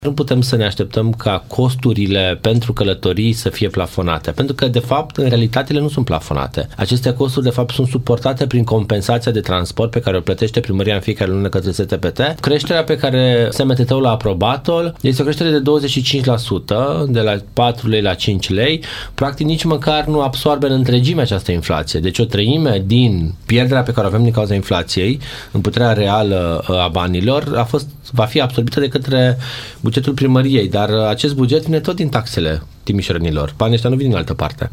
Viceprimarul Ruben Lațcău spune că plafonarea tarifelor este nesustenabilă odată cu majorarea costurilor impuse de salarii, combustibili și de inflație.